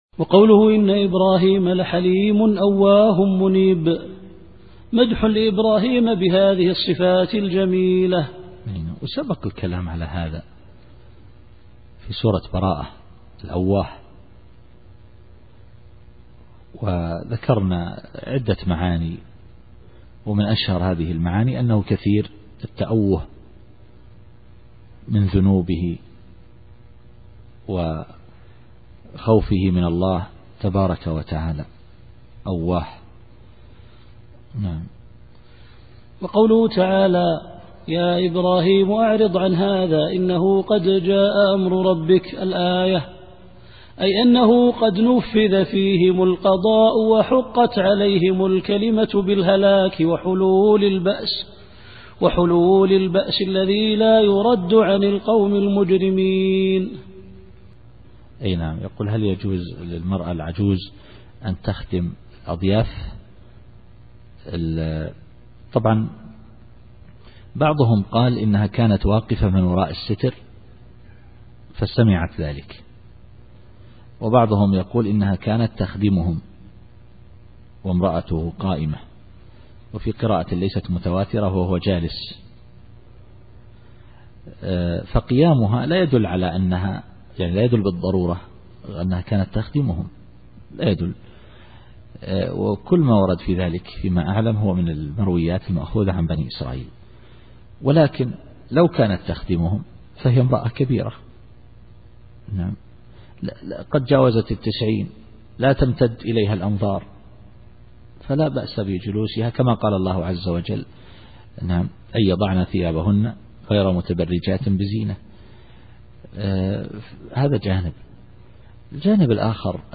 التفسير الصوتي [هود / 75]